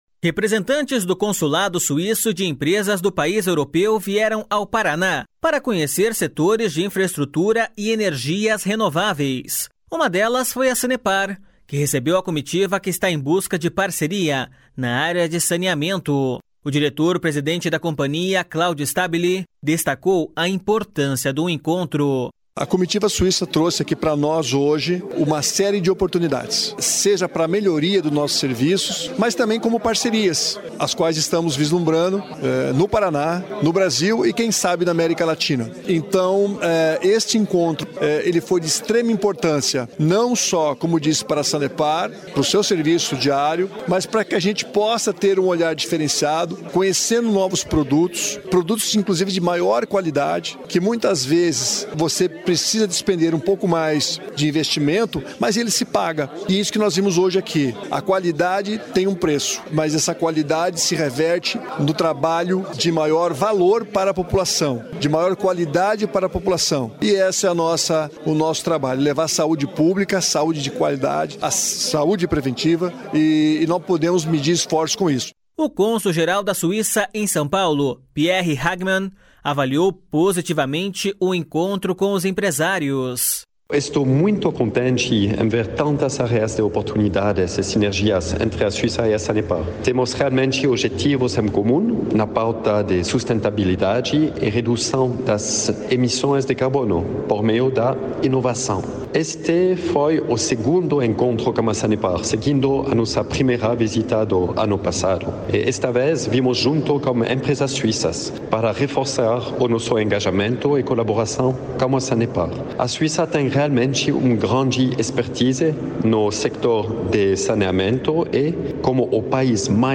O cônsul-geral da Suíça em São Paulo, Pierre Hagmann, avaliou positivamente o encontro com os empresários.// SONORA PIERRE HAGMANN.//